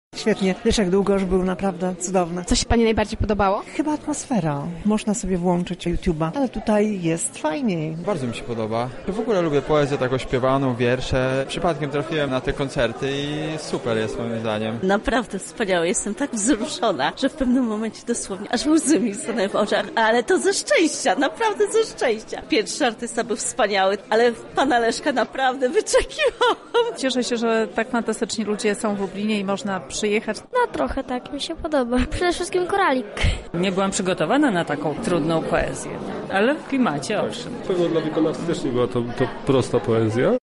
Oto co na temat wydarzenia myślała publiczność: